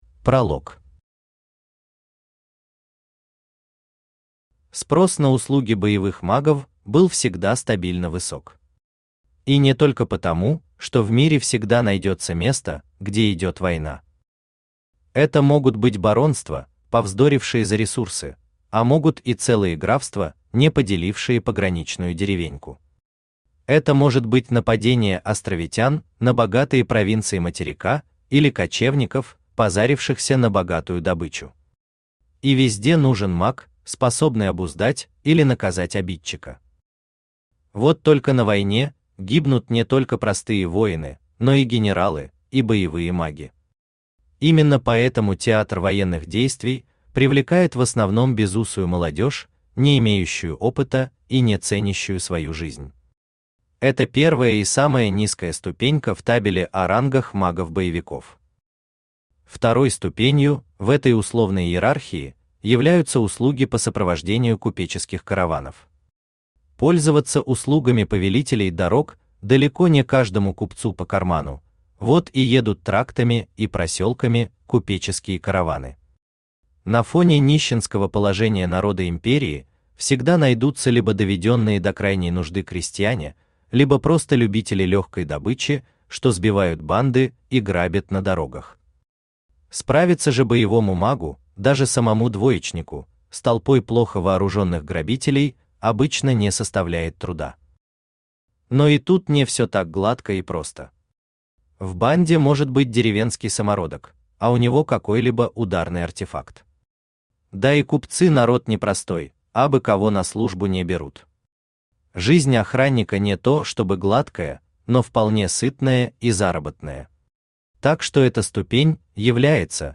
Аудиокнига Обреченный на скитания.
Aудиокнига Обреченный на скитания. Книга 9. Трон Империи Автор Сергей Мясищев Читает аудиокнигу Авточтец ЛитРес.